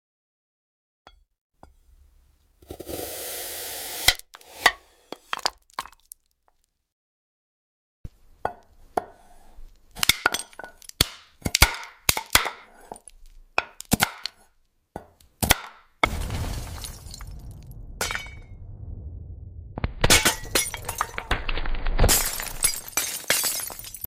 This surreal AI ASMR video sound effects free download
This surreal AI ASMR video reimagines your favorite sodas. Watch as glass versions of Coca-Cola, Fanta, and Sprite cans are sliced, diced, and shattered with impossibly satisfying sounds.